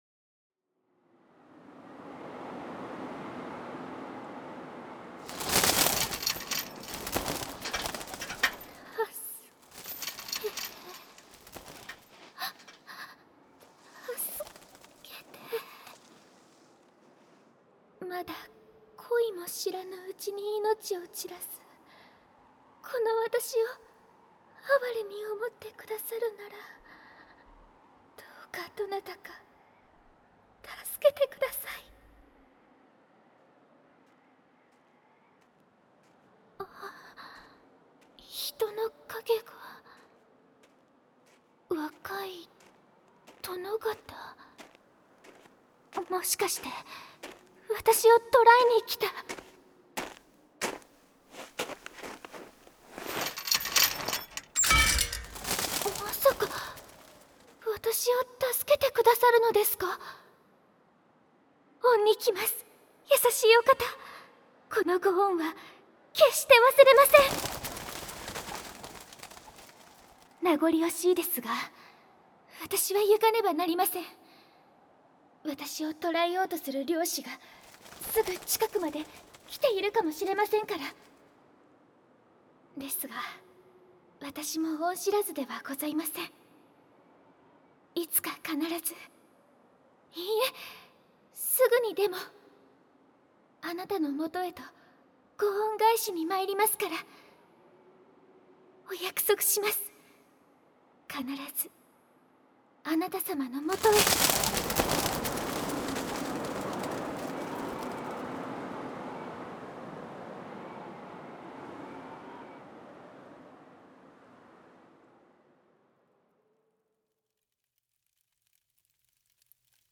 环绕音 ASMR